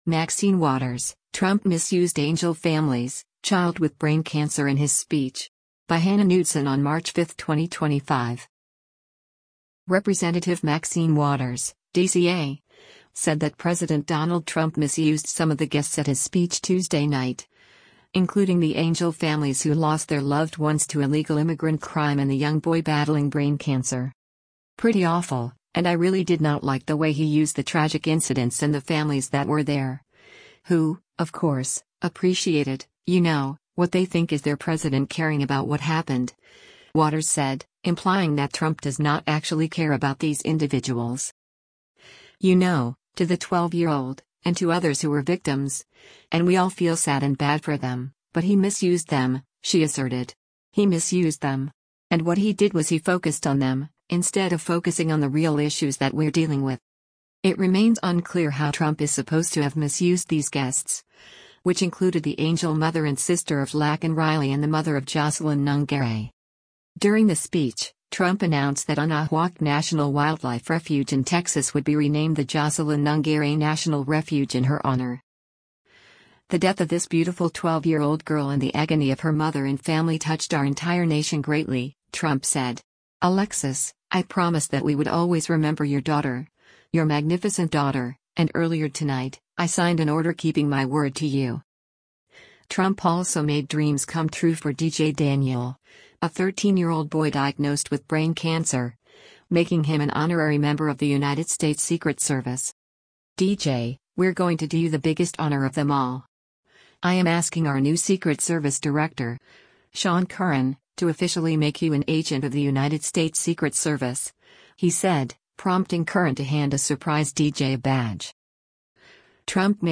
“And we don’t know everything that Elon Musk has done, with his high technology ass,” Waters, 86, said during a video stream.